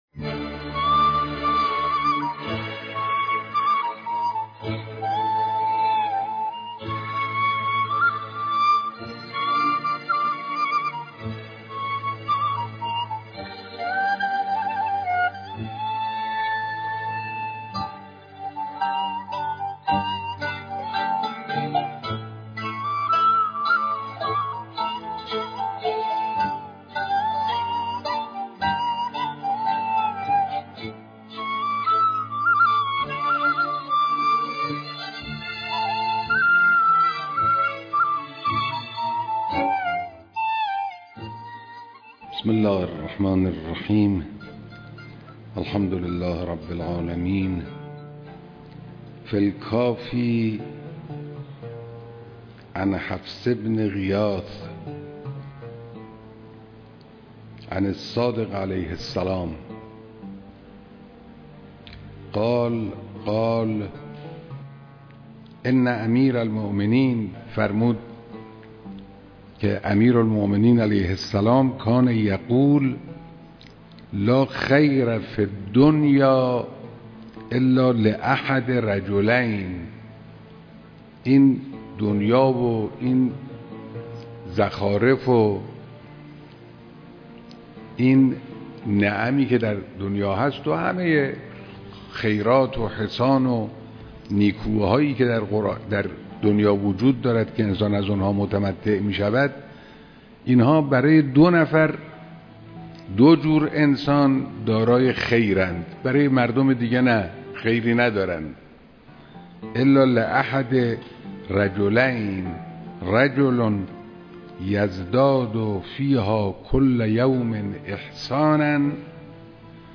این کلیپ یکی از برنامه های  مجموعه  «عطر ماندگار» است که تا کنون چند دوره از آن شبها قبل از خبر ساعت 21 شبکه یک سیما پخش گردیده است و شبکه قرآن سیما نیز آن را بازپخش نموده است ، این مجموعه شامل قطعات کوتاهی از شرح احادیث اخلاقی است که حضرت آیت‌الله خامنه‌ای در ابتدای درس خارج فقه خود بیان می‌کنند.